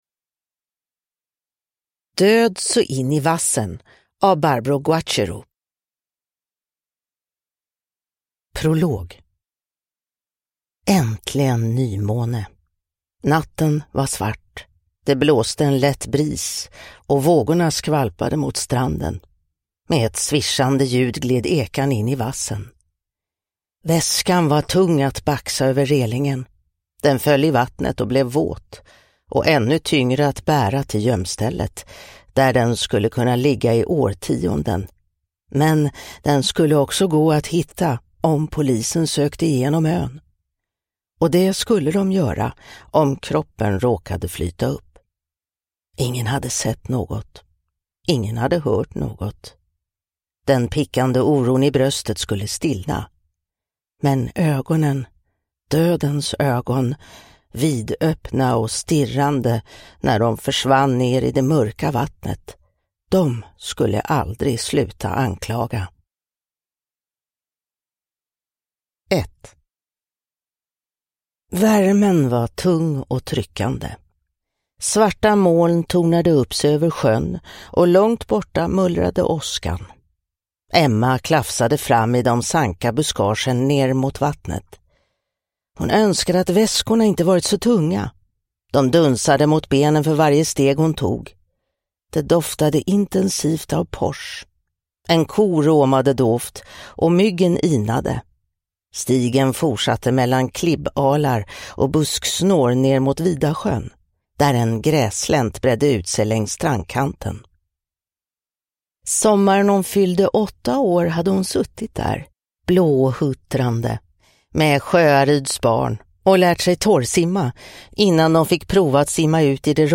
Död så in i vassen – Ljudbok